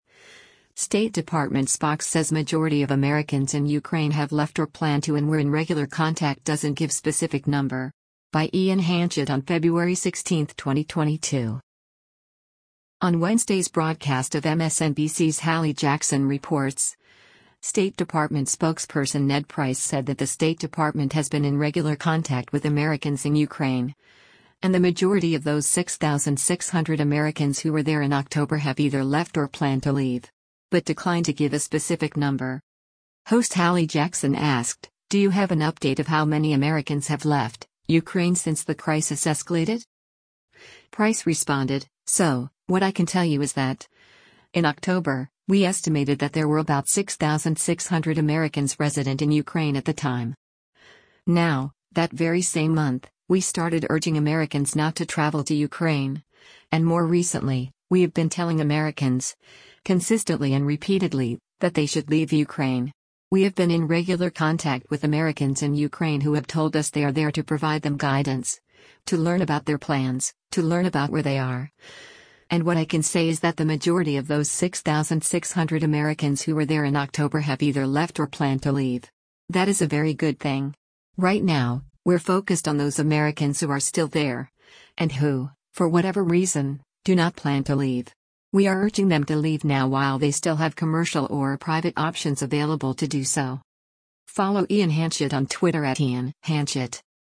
On Wednesday’s broadcast of MSNBC’s “Hallie Jackson Reports,” State Department Spokesperson Ned Price said that the State Department has “been in regular contact” with Americans in Ukraine, and “the majority of those 6,600 Americans who were there in October have either left or plan to leave.”
Host Hallie Jackson asked, “Do you have an update of how many Americans have left…Ukraine since the crisis escalated?”